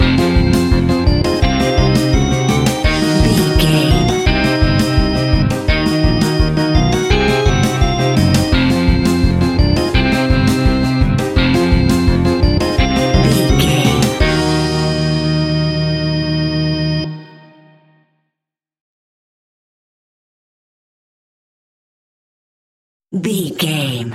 Atonal
tension
ominous
eerie
instrumentals
horror music
Horror Pads
horror piano
Horror Synths